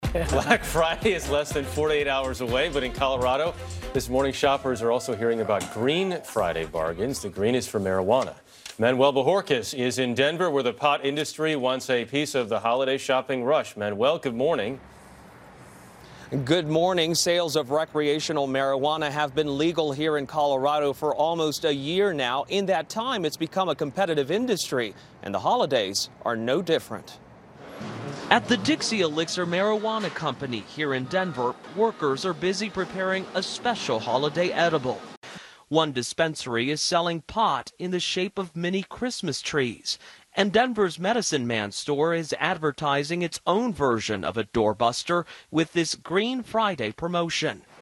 This Morning on Wednesday again touted the wonder of legalized marijuana in Colorado.